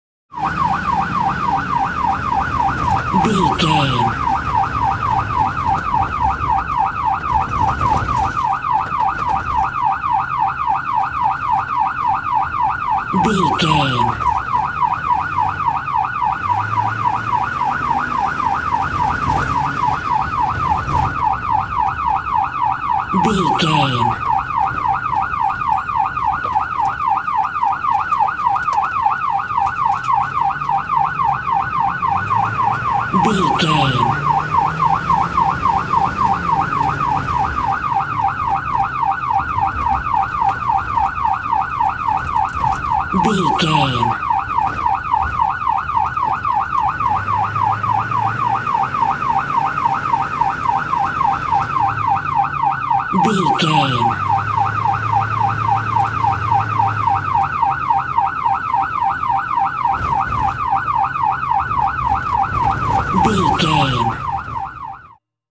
Ambulance Int Drive Engine Short Siren
Sound Effects
urban
chaotic
emergency